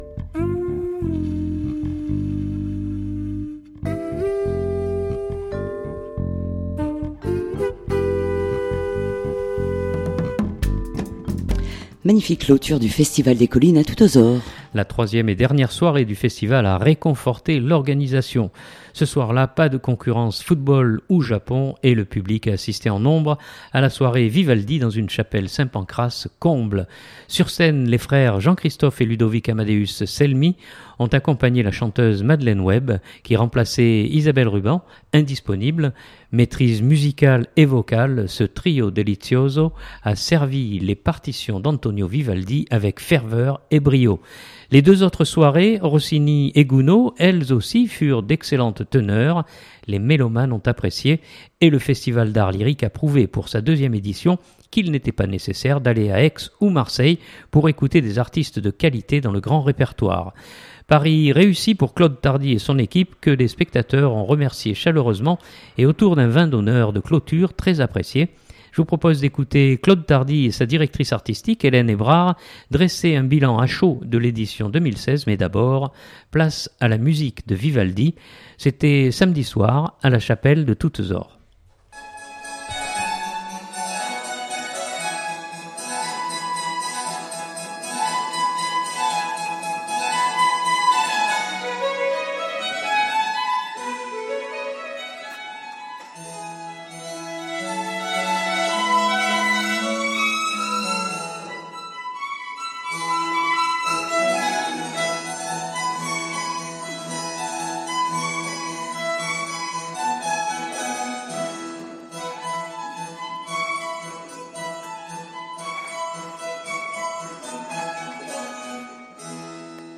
C’était samedi soir à la chapelle de Toutes-Aures.